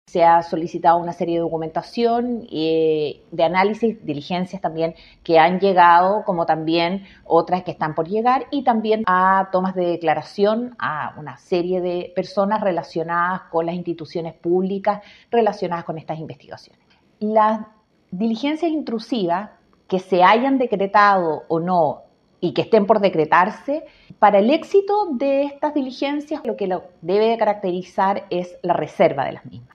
La respuesta a estas críticas la entrego la fiscal Anticorrupción, María José Aguayo, puntualizando que las medidas intrusivas se decretan bajo reserva para su éxito, pero confirmando la obtención de documentos y la toma de declaraciones a involucrados en las causas abiertas.